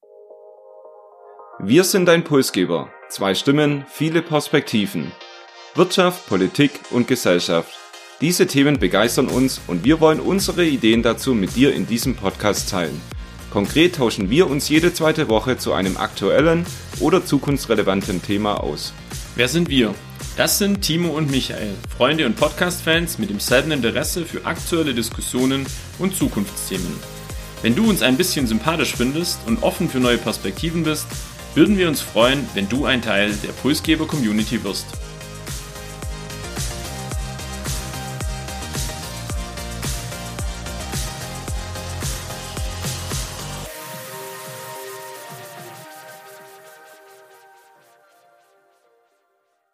Wir sind dein Pulsgeber - zwei Stimmen, viele Perspektiven. Wirtschaft, Politik und Gesellschaft - diese Themen begeistern uns und wir wollen unsere Ideen dazu mit dir in diesem Podcast teilen.